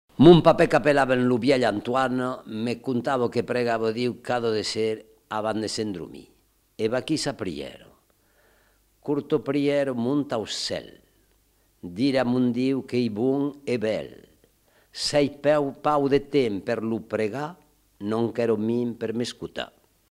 Aire culturelle : Haut-Agenais
Genre : conte-légende-récit
Type de voix : voix d'homme
Production du son : récité
Classification : prière